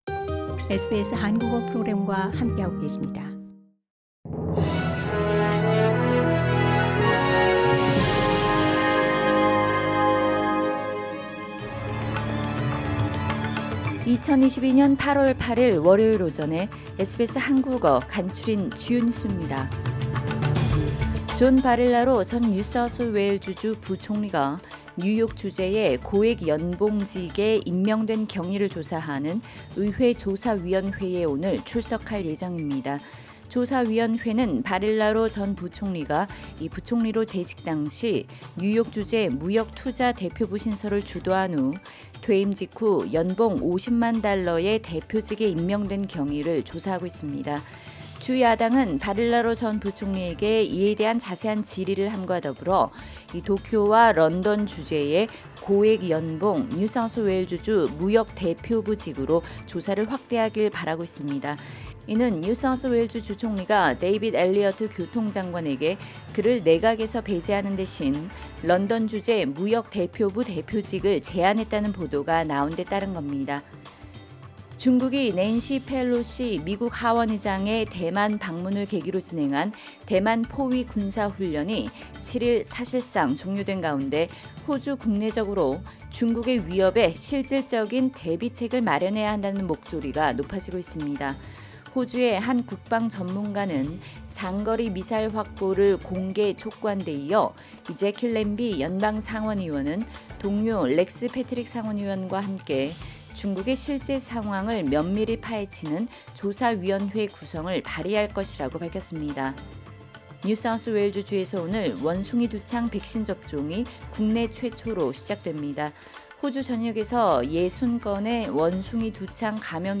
SBS 한국어 아침 뉴스: 2022년 8월 8일 월요일